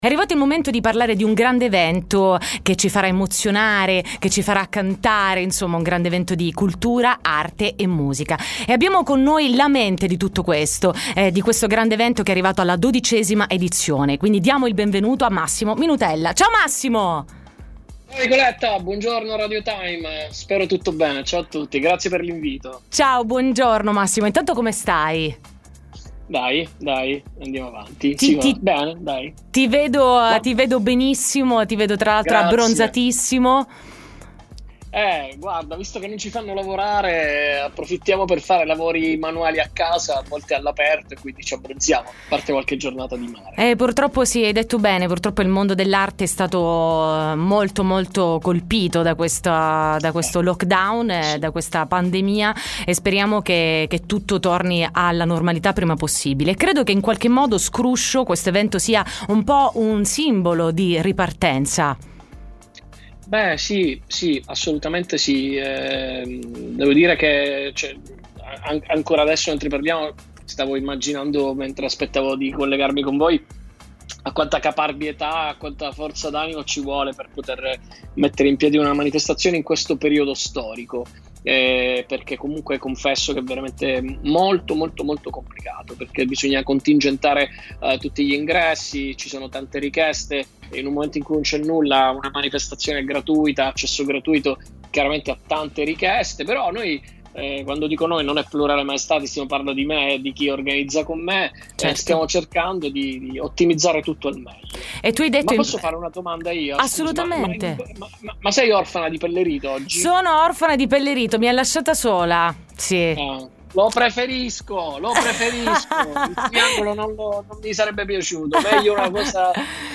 S.T. Intervista Dinastia